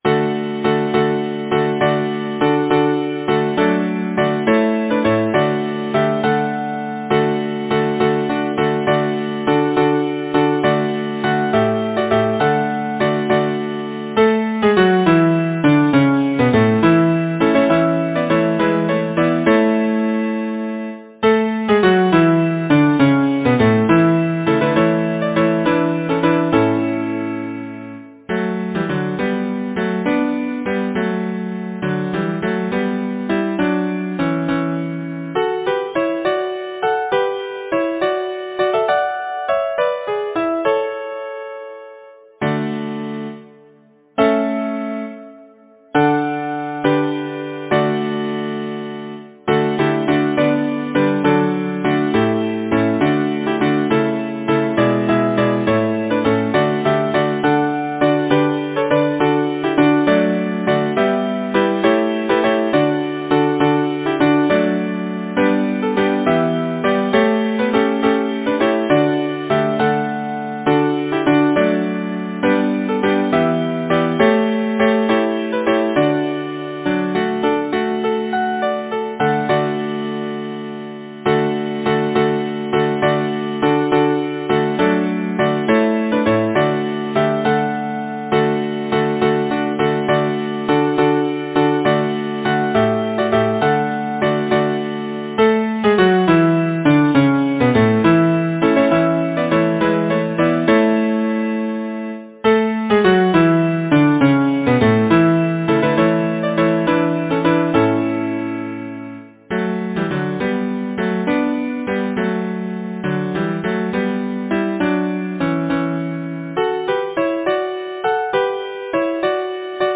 Title: O, the merry harvest-time Composer: George J. Webb Lyricist: Number of voices: 4vv Voicing: SATB Genre: Secular, Partsong
Language: English Instruments: A cappella